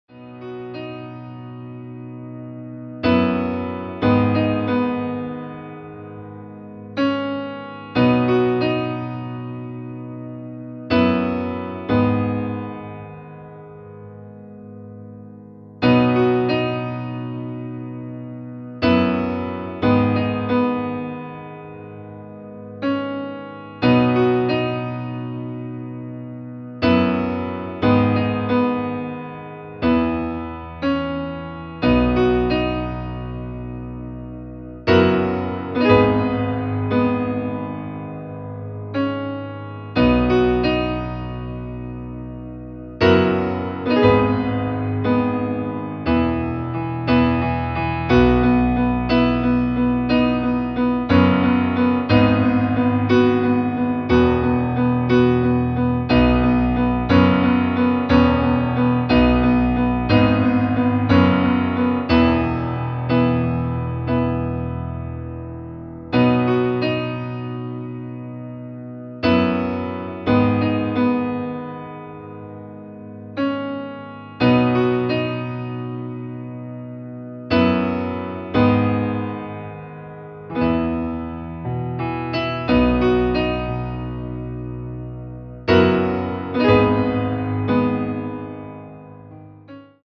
• Tonart:  D Dur, H Dur
• Das Instrumental beinhaltet keine Leadstimme